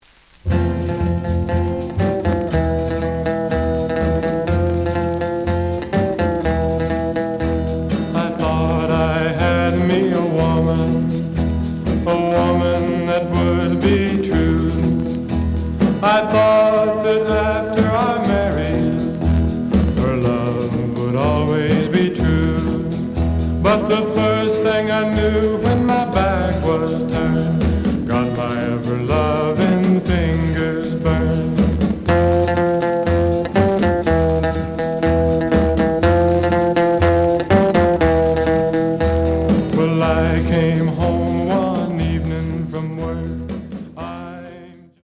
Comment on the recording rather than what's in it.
The audio sample is recorded from the actual item. Slight sound cracking on both sides.